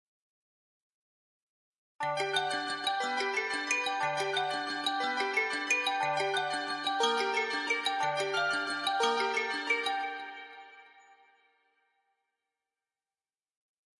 May marimba 2018
描述：crafted and layered sounds
声道立体声